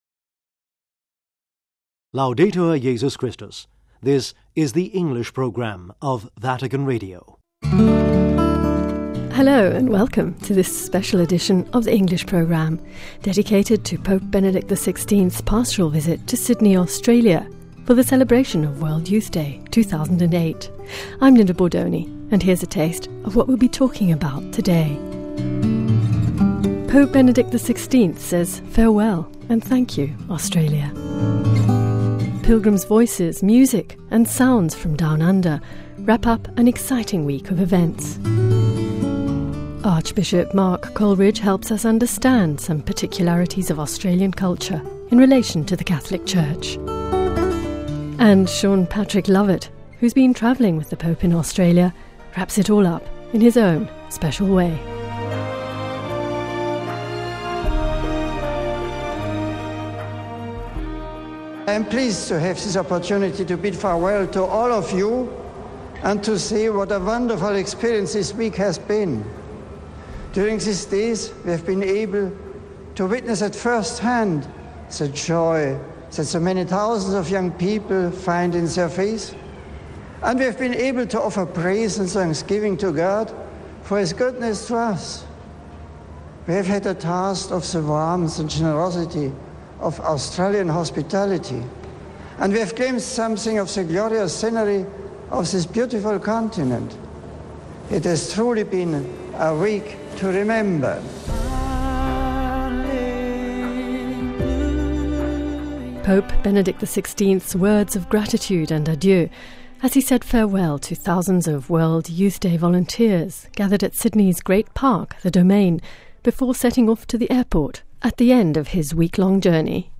FAREWELL - Pope Benedict XVI says farewell and thank you to Australia... VOX PILGRIMUS! - Pilgrims' voices, music and sounds from down-under wrap up an exciting week of events... A PARTICULAR BUZZ - Archbishop Mark Coleridge helps us understand some peculiarities of Australian culture in relation to the Catholic Church...